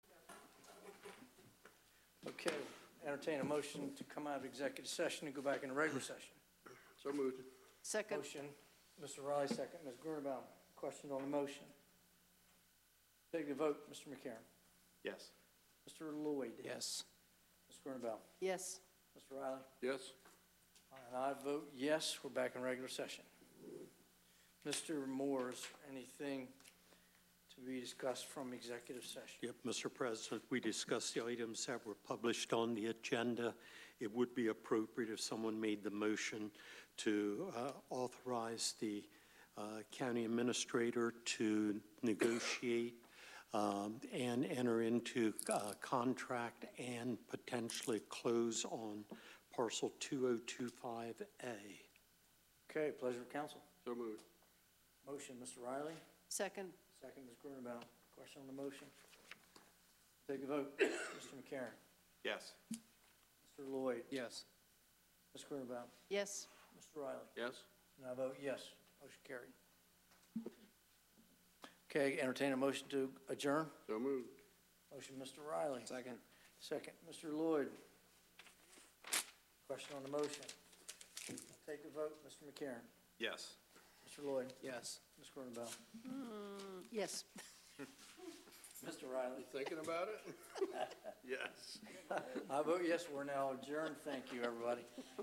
Meeting location: Council Chambers, Sussex County Administrative Office Building, 2 The Circle, Georgetown
Meeting type: County Council